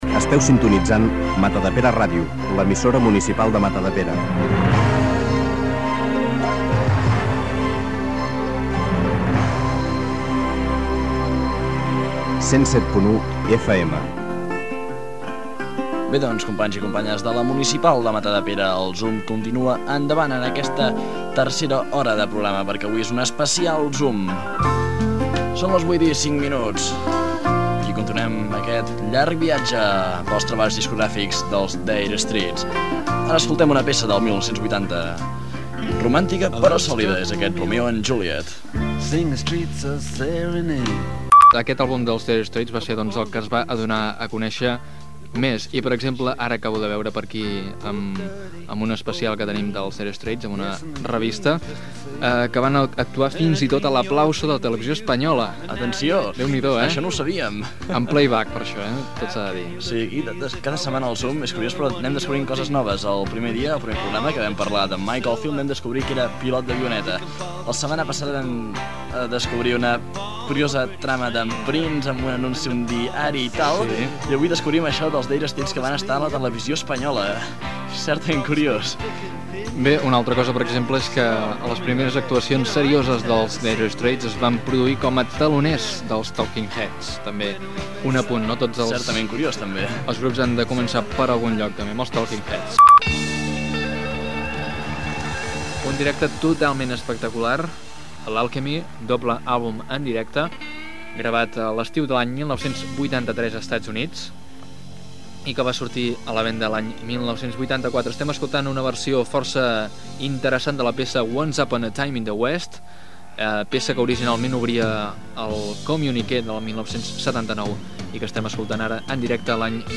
Indicatiu de l'emissora. Presentació de la tercera hora. Monogràfic dedicat a la banda britànica Dire Straits.
Musical